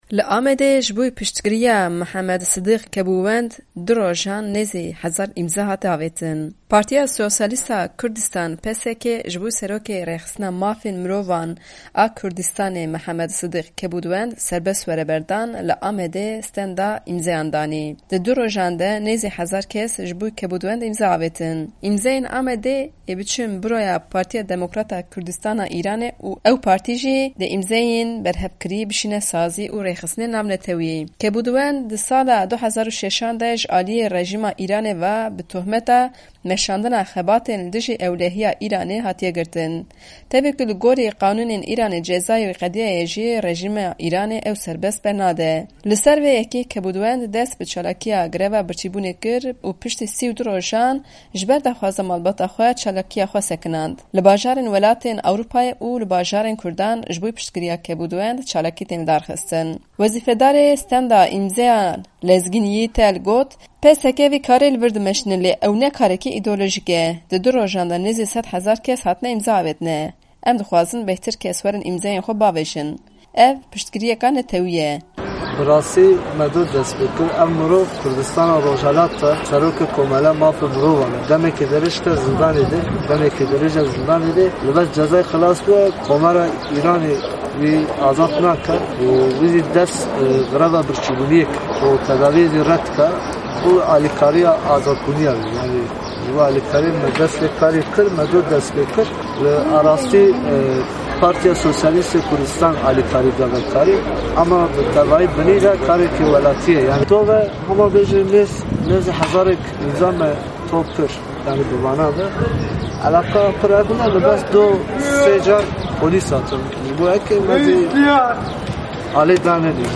Raport bi deng